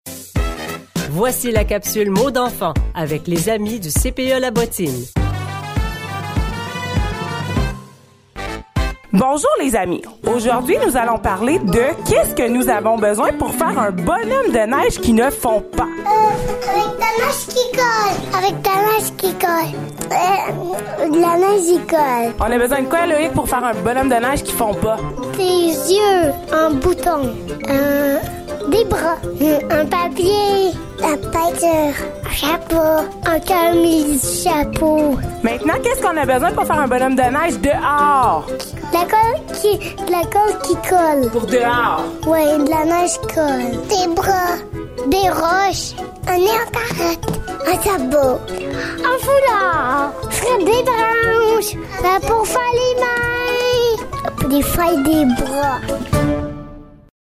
Les enfants d'âge préscolaire du CPE La Bottine nous parlent de la façon de faire des bonhommes de neige.